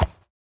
minecraft / sounds / mob / horse / soft2.ogg